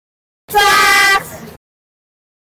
Efek suara Chuaks
Kategori: Suara meme
Keterangan: Efek suara 'Chuaks' dalam edit video sering dipakai untuk menambah kesan lucu dan mengejutkan, membuat momen jadi lebih menarik dan menghibur penonton.
efek-suara-chuaks-id-www_tiengdong_com.mp3